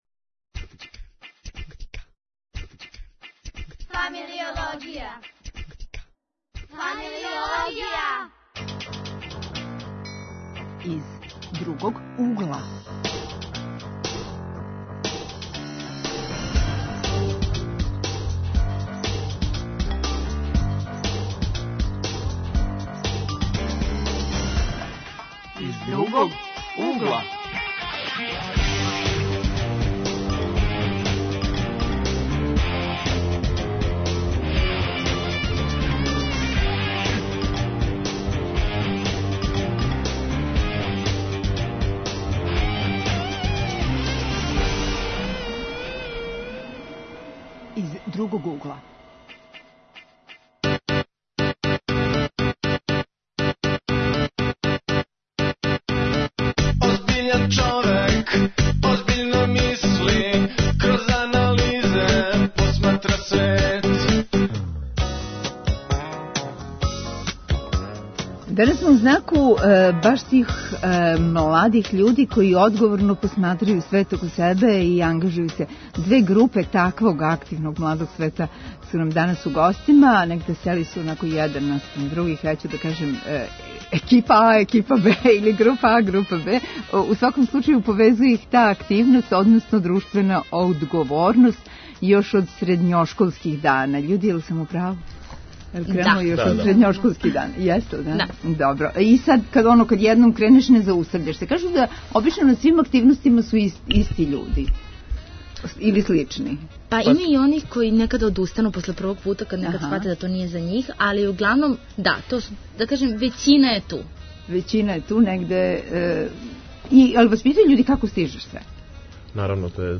Гости: средњошколци и студенти, упознају нас са Balkans let's get up! организацијом, и позивају на БИМУН конференцију 2020.